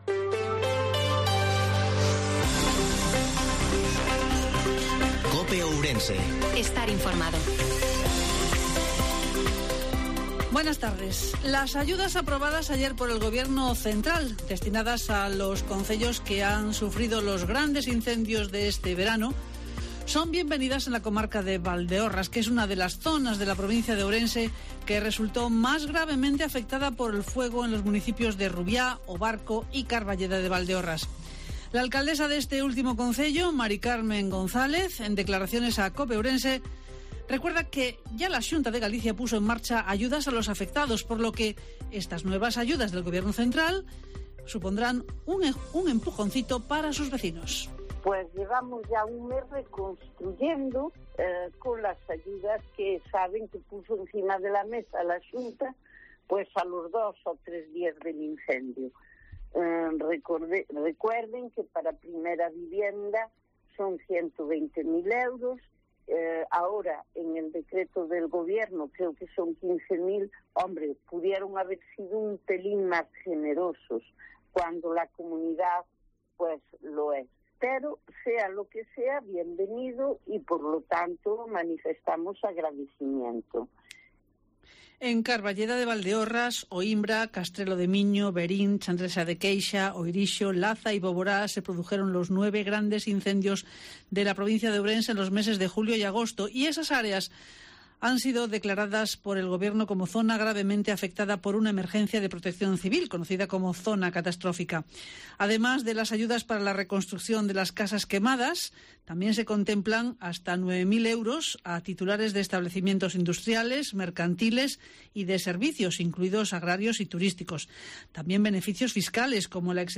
INFORMATIVO MEDIODIA COPE OURENSE-24/08/2022